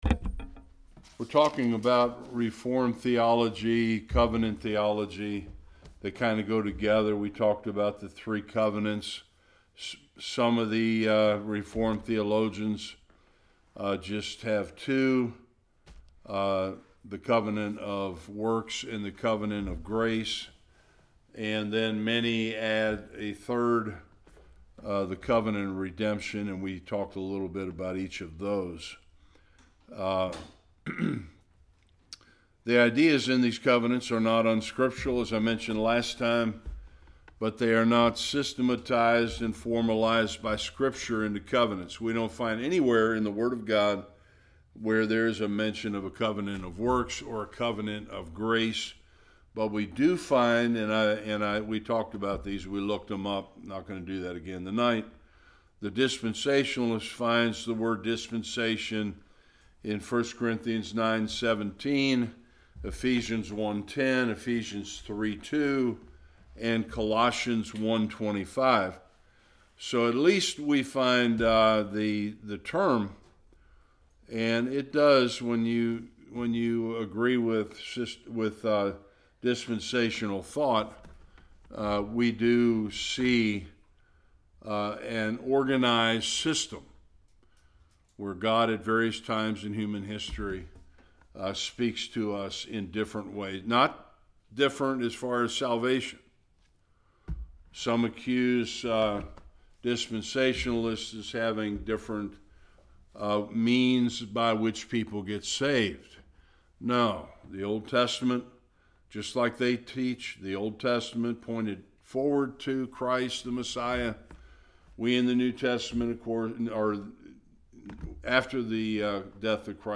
Various Passages Service Type: Bible Study What is the Biblical basis for Covenant theology?